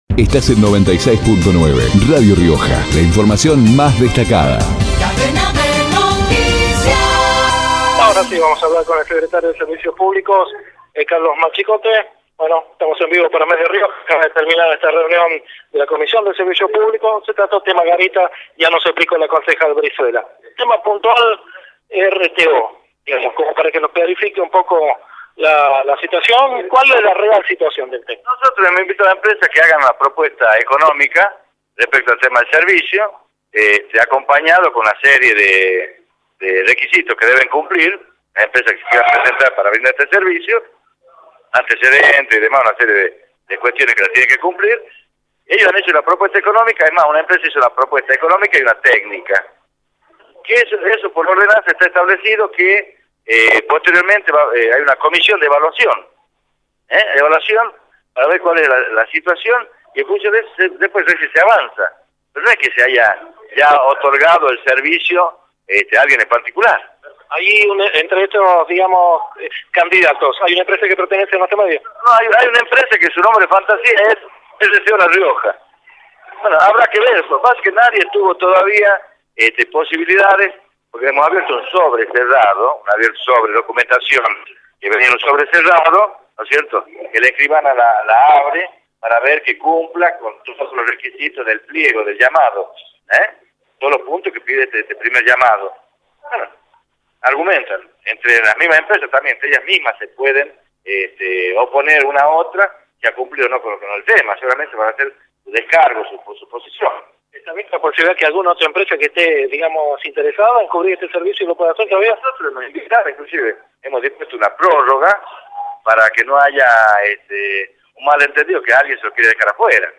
Carlos Machicote, secretario de Servicios Públicos, por Radio Rioja